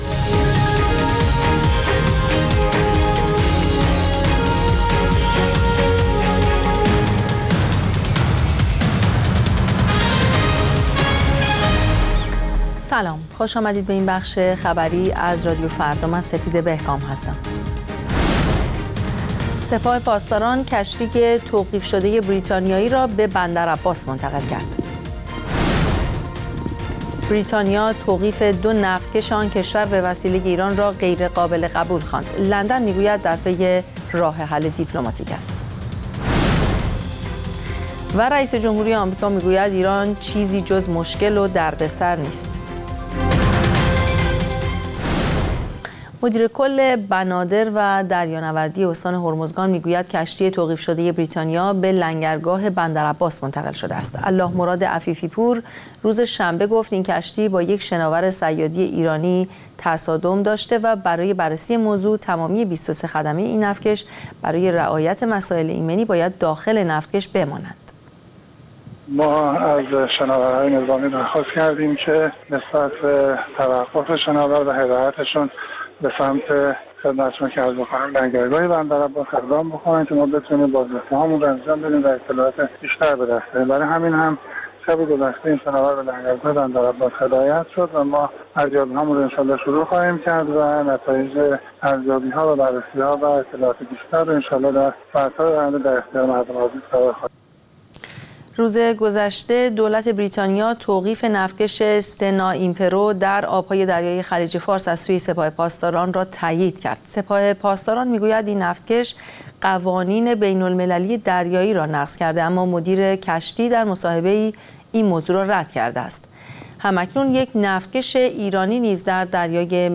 اخبار رادیو فردا، ساعت ۱۱:۰۰